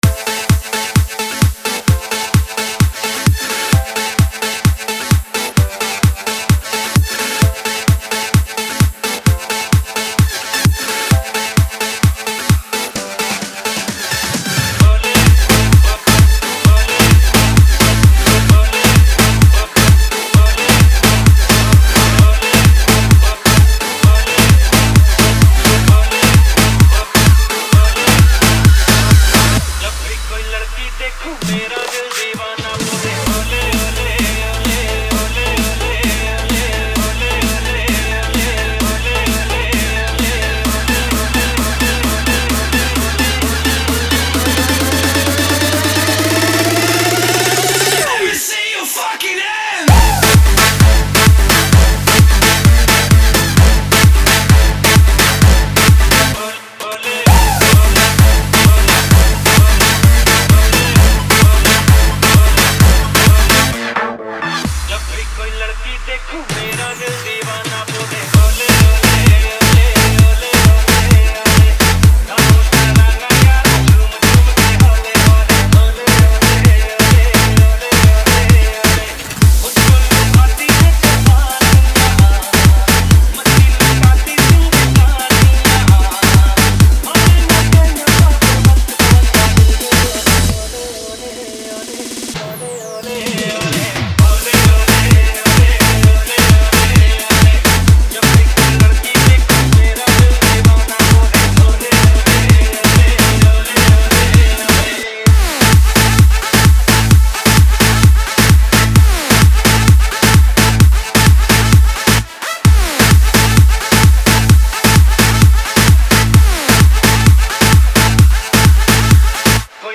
Retro Single Remixes